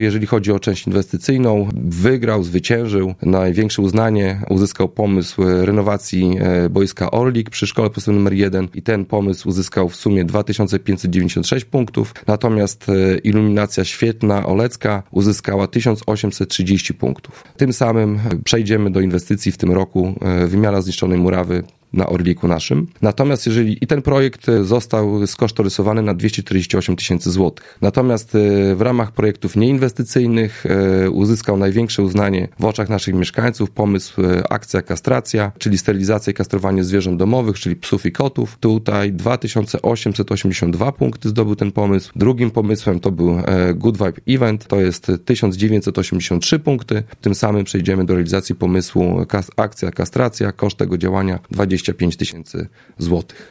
– Największą ilość głosów zdobył projekt wymiany zniszczonej murawy boiska przy Szkole Podstawowej nr 1 oraz akcja kastracji psów – przekazał na antenie Radia 5 Karol Sobczak, burmistrz Olecka.